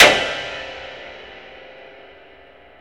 SI2 PIANO0IR.wav